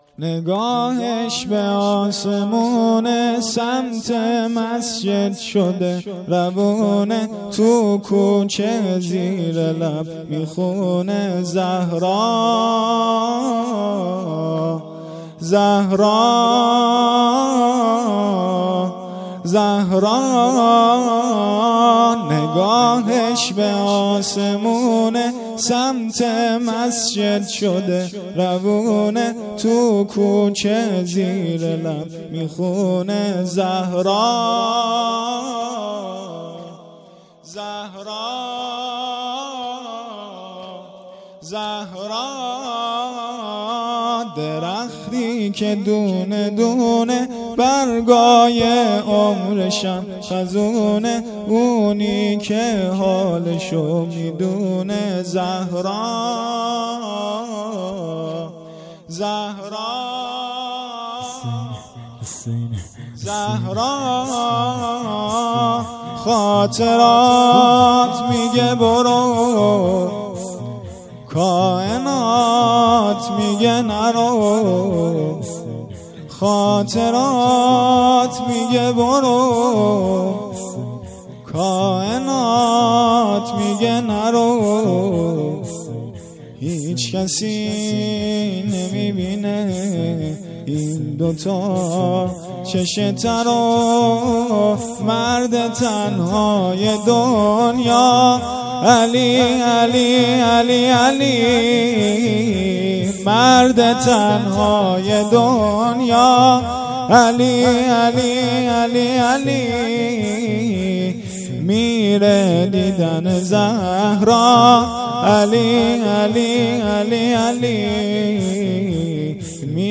مراسم احیای شب های قدر شب ۱۸ رمضان شهادت امیرالمومنین علیه السلام ۱۴۰۳